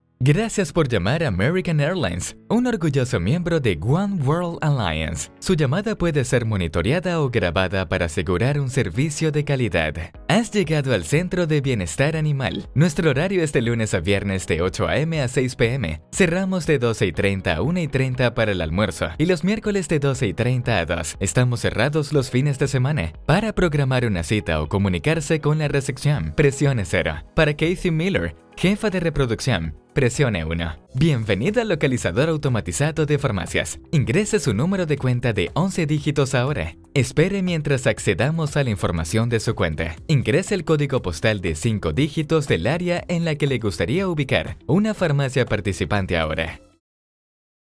Español (América Latina)
Comercial, Joven, Natural, Travieso, Empresarial
Telefonía